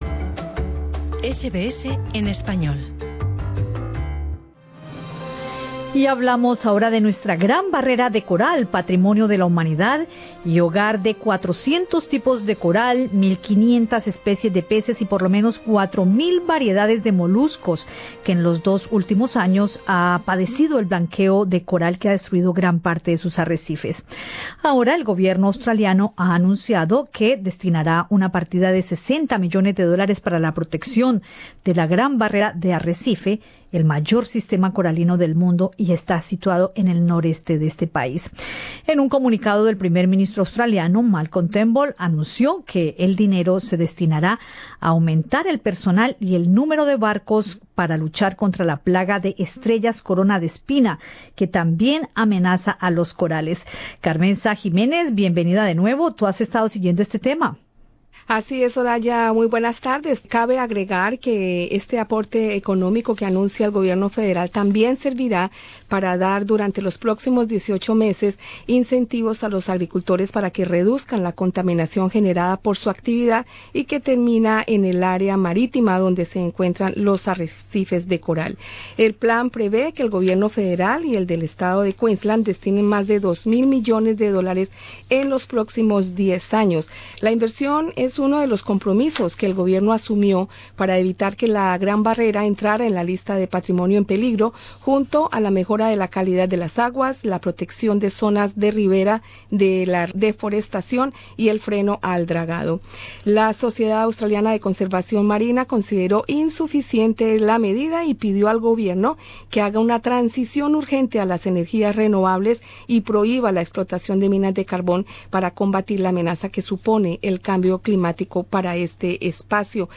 Source: Escucha arriba en nuestro podcast la entrevista en la que la científica explica cómo la ciudadanía y los turistas pueden también contribuir a la protección de nuestra gran barrera de coral.